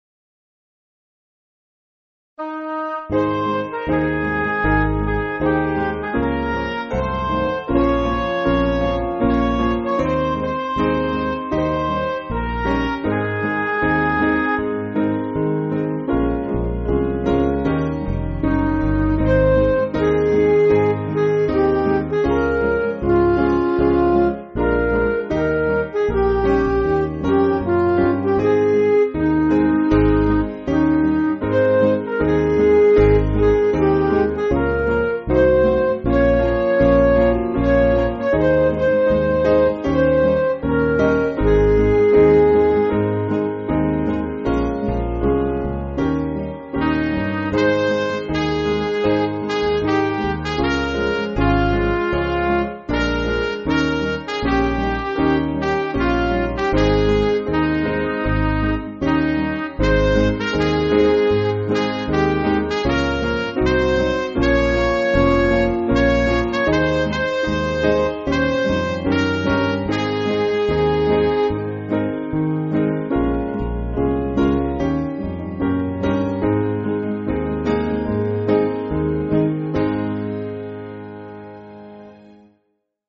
Piano & Instrumental
(slower)